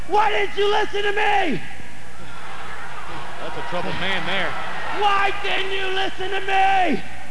Do you ever wonder what RAVEN sounds like when he's mad?
rav_mad.wav